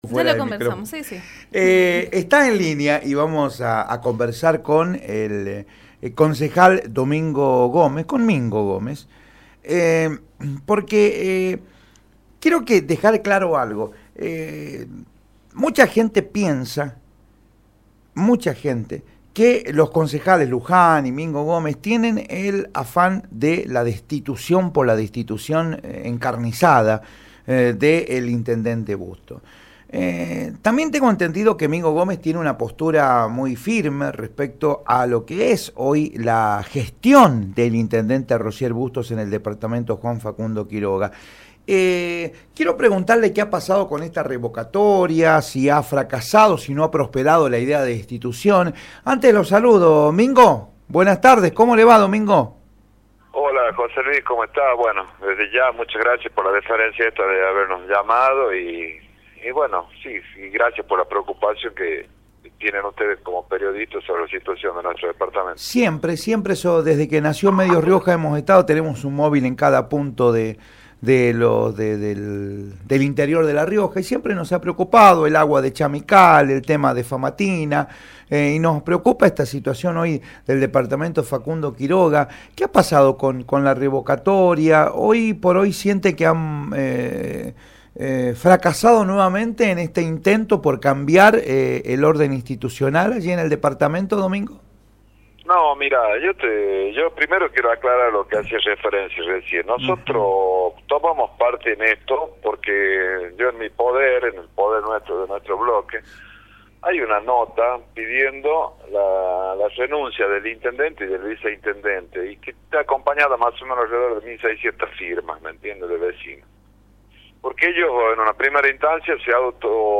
Domingo Gómez, concejal, por Radio Rioja